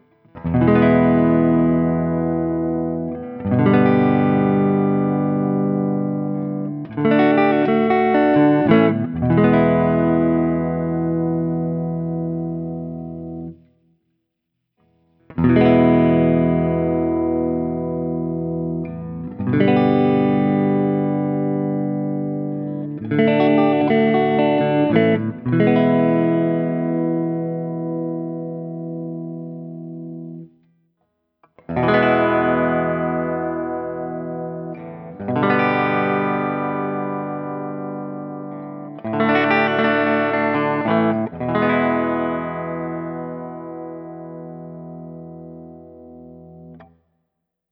Open Chords #1
As usual, for these recordings I used my normal Axe-FX II XL+ setup through the QSC K12 speaker recorded direct into my Macbook Pro using Audacity. I recorded using the ODS100 Clean patch, as well as the JCM-800 and one through a setting called Citrus which is a high-gain Orange amp simulation.
For each recording I cycle through the neck pickup, both pickups, and finally the bridge pickup. All knobs on the guitar are on 10 at all times.